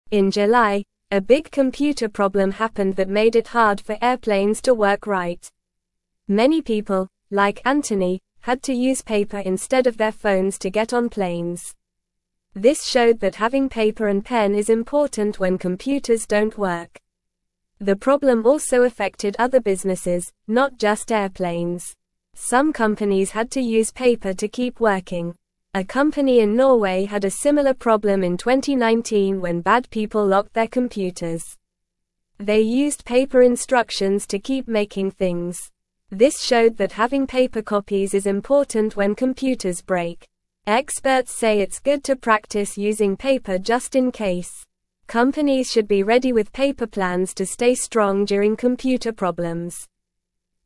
Normal
English-Newsroom-Beginner-NORMAL-Reading-Paper-is-important-when-computers-dont-work.mp3